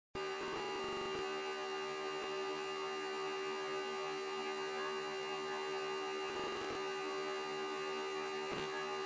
なんやこのラップ音
がーーーーって言ってる
ラップ音がガーーーーー
なんかカメラ壊れたみたいな音
「ぶぃぃいいい」みたいな
冷蔵庫のおとに近いかな